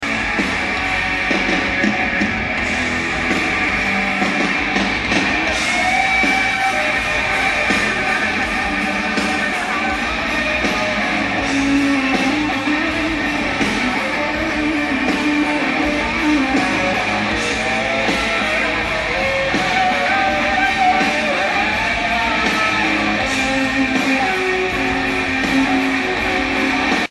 Un public assez euhporique.